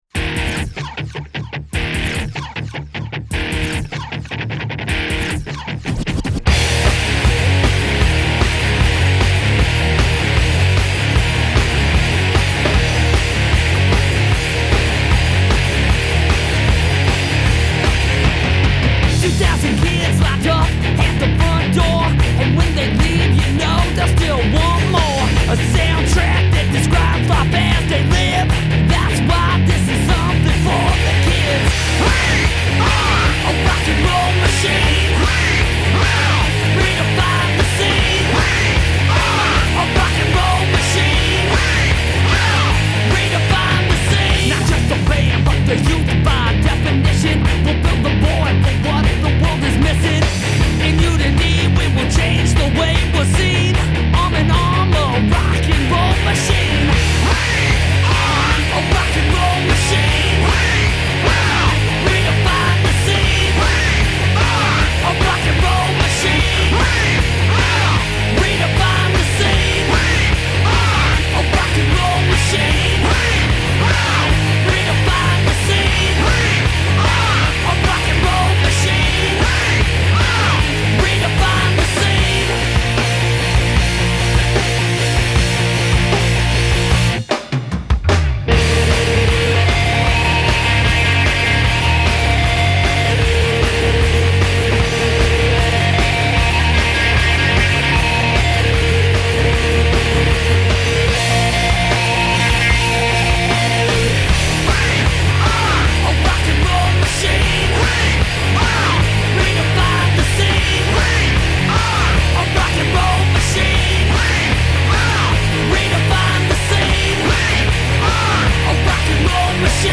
Rock/Metal
Rock